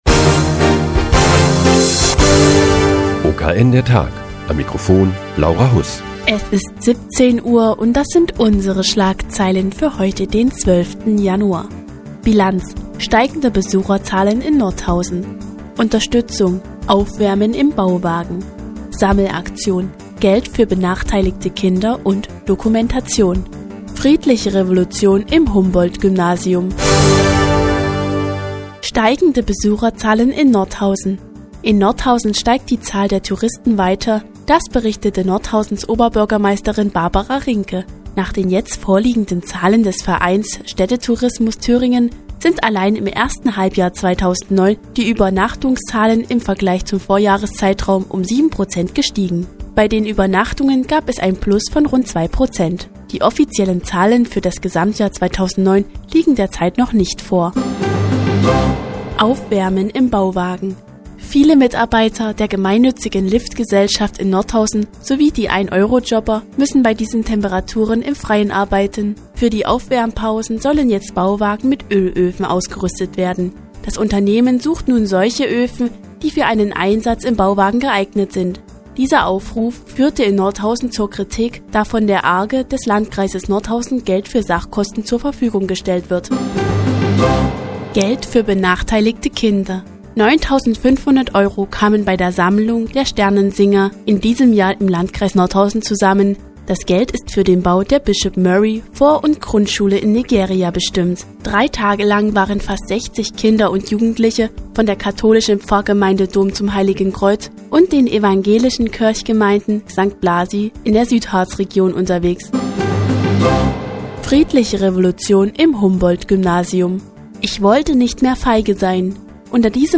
Die tägliche Nachrichtensendung des OKN ist nun auch in der nnz zu hören. Heute geht es um die Sammelaktion der Sternensinger und eine Ausstellung im Humboldt-Gymnasium.